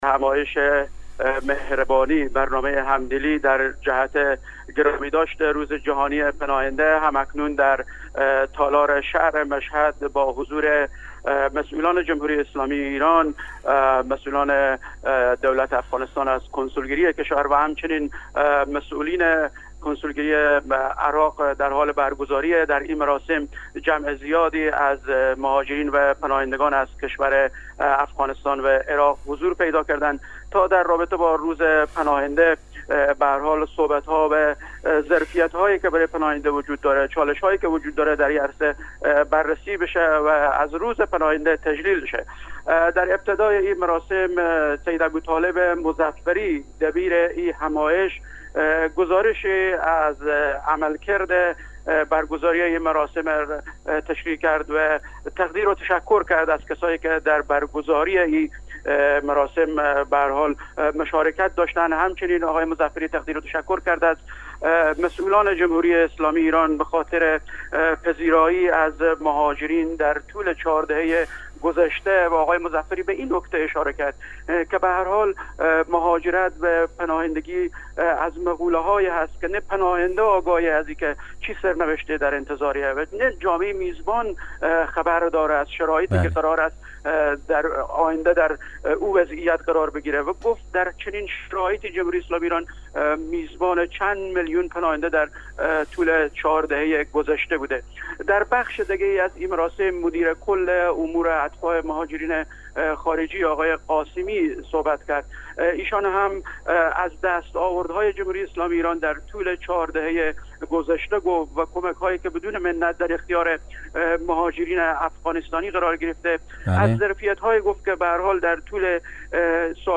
همزمان با روز جهانی پناهنده مراسم ویژه ای در شهر مشهد با حضور گسترده مهاجران افغانستانی مقیم خراسان رضوی برگزار شد.